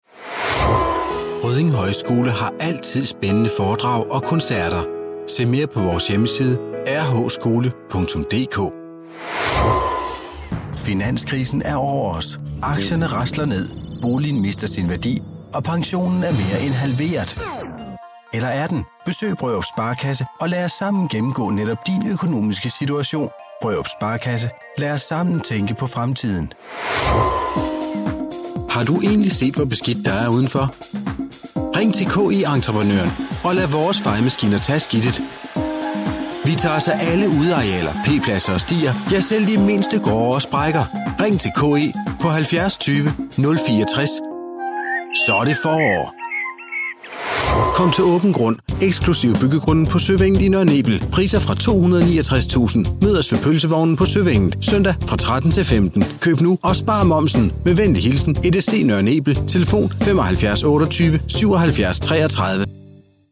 Danish voice over from a leading voice over artist - Native from Denmark
Sprechprobe: Werbung (Muttersprache):